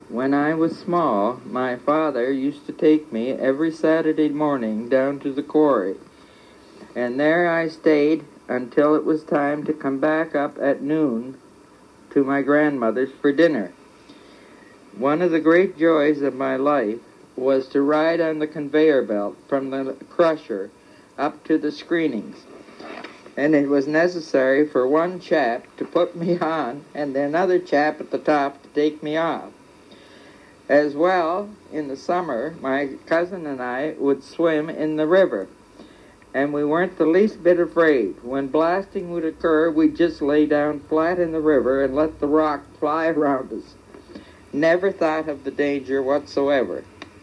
Entretien
lors d’une présentation publique vers 1975